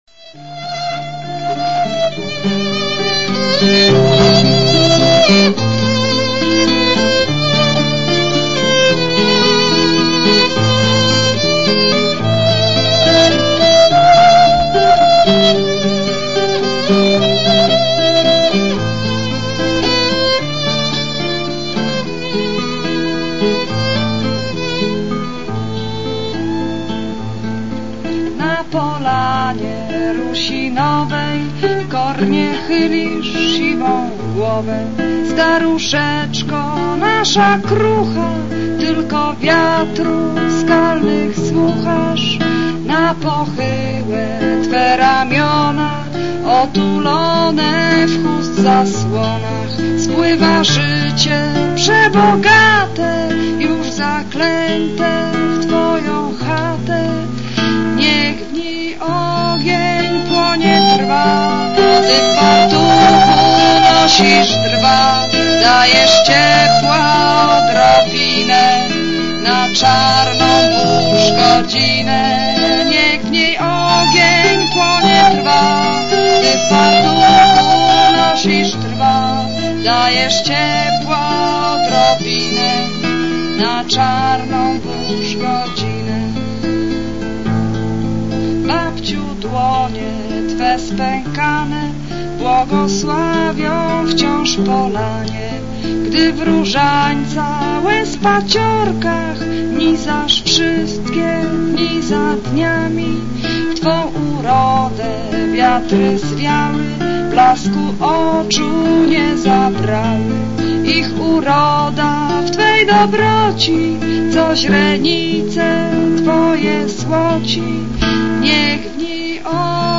4) owce